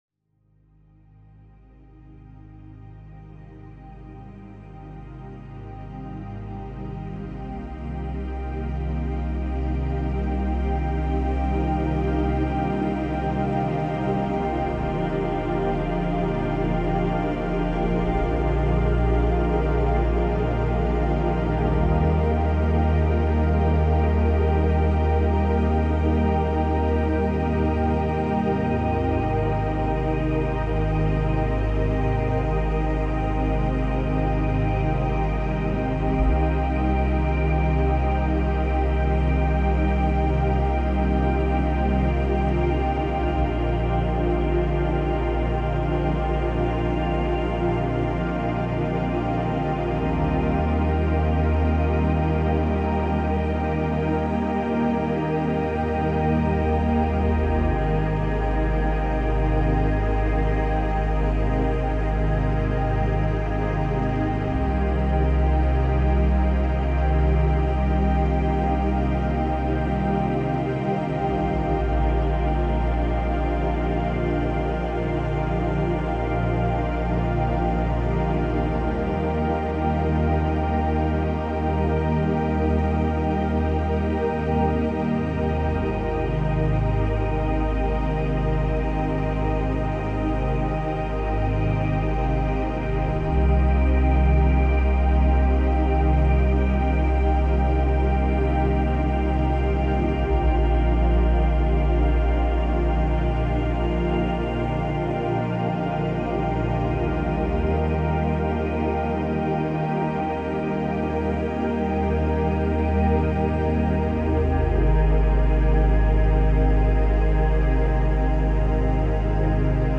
La fréquence 625 hz nettoie en profondeur les différents aspects de la déficience mentale
625-hz-Frequence-vibratoire-contre-la-deficience-mentale.mp3